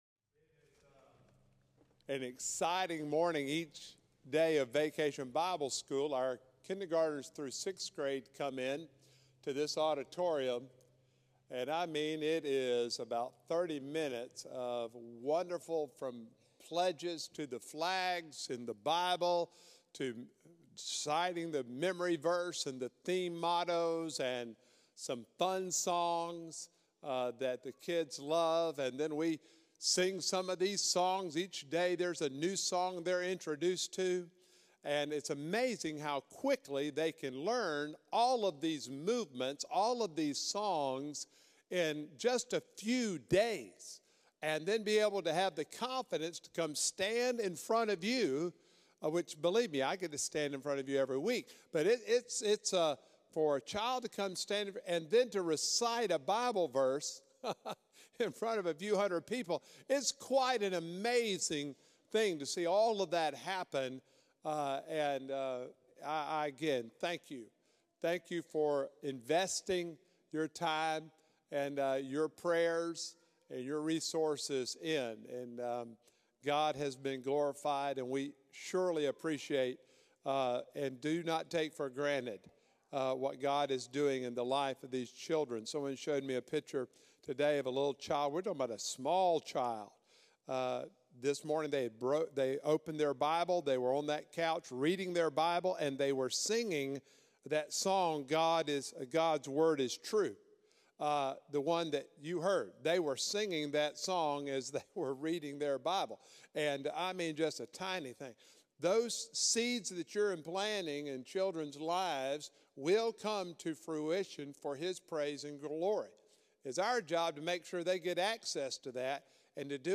Sermons
June-9-2024-Sermon-Audio.mp3